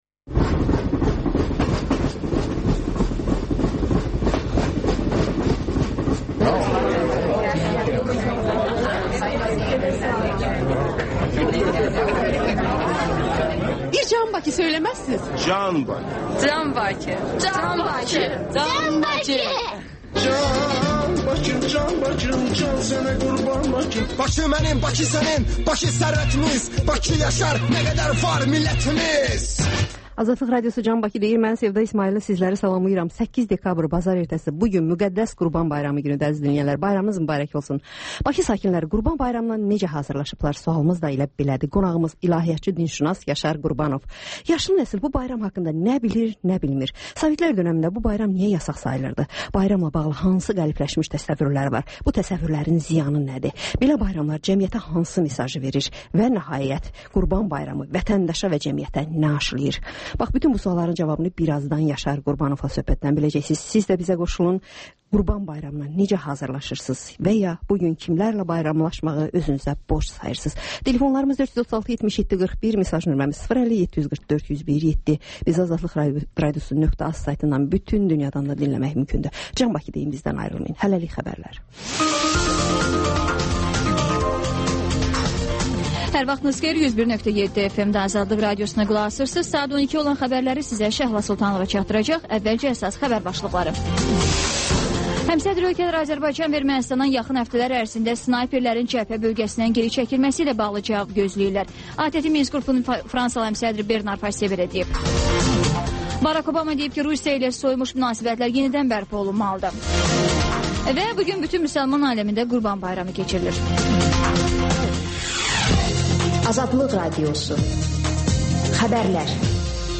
Xəbərlər, sonra CAN BAKI verilişi: Bakının ictimai və mədəni yaşamı, düşüncə və əyləncə həyatı… (Təkrarı saat 14:00-da)